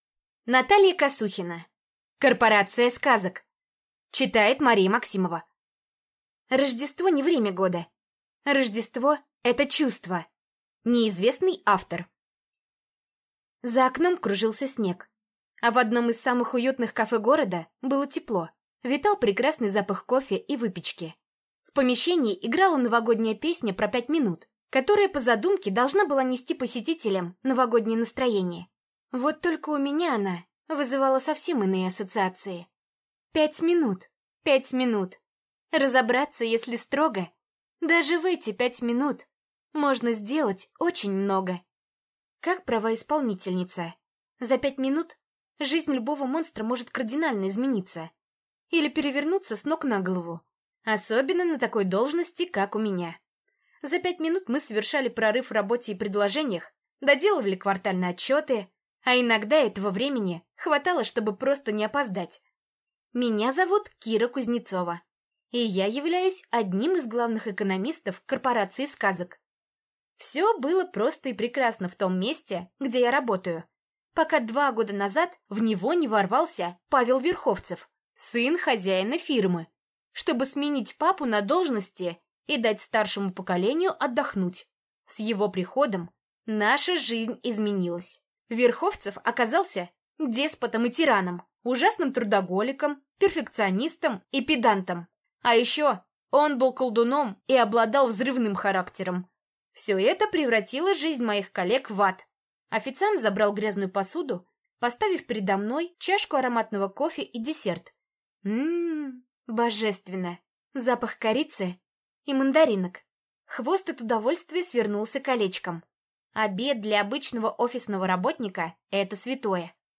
Аудиокнига Корпорация сказок | Библиотека аудиокниг
Прослушать и бесплатно скачать фрагмент аудиокниги